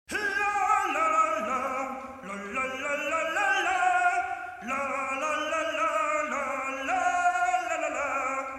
• Качество: 192, Stereo
поп
громкие
веселые
dance
итало-диско